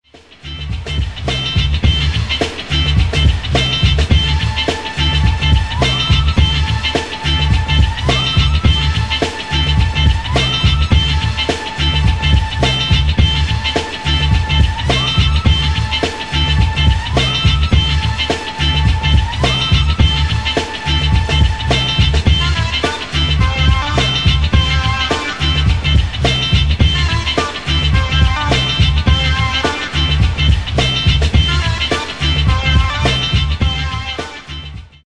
12''Inch Extended Instrumental Mix